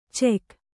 ♪ cek